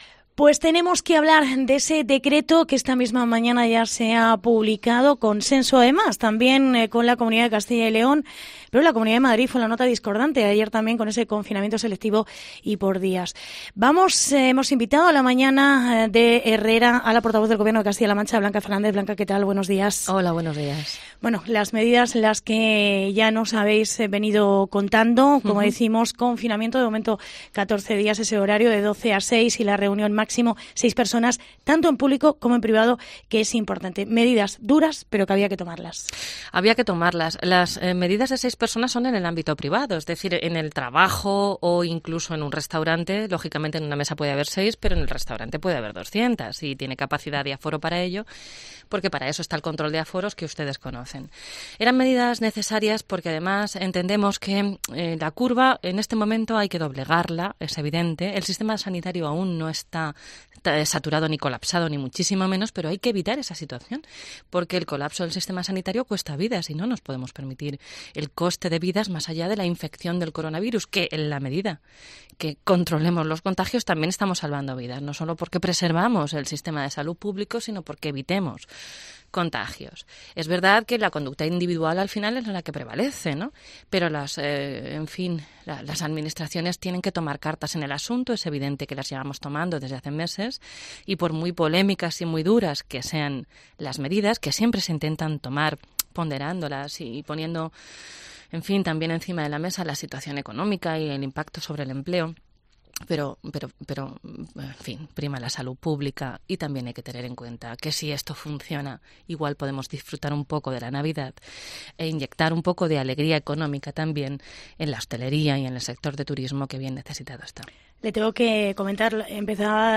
Blanca Fernández. Portavoz del gobierno de CLM en los estudios de COPE